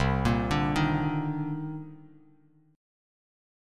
Cm9 Chord
Listen to Cm9 strummed